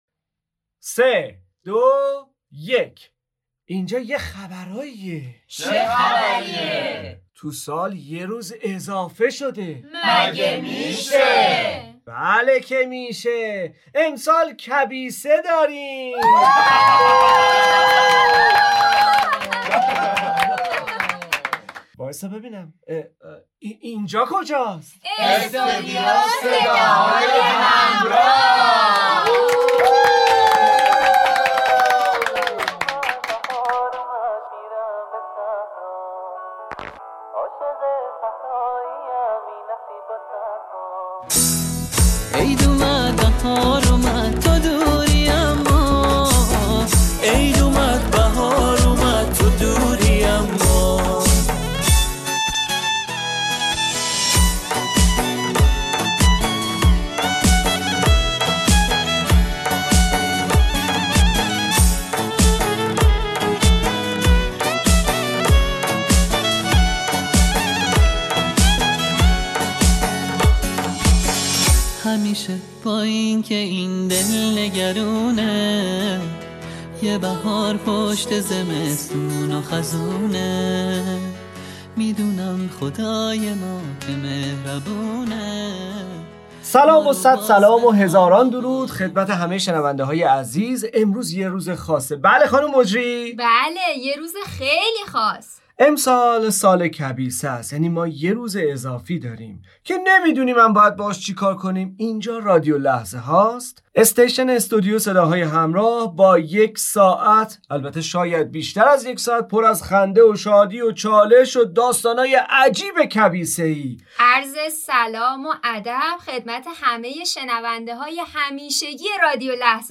ویژه برنامه ی رادیویی سال کبیسه برنامه ای شاد و طنز هست که توسط کار گروه استودیو صداهای همراه ضبط و پخش شده.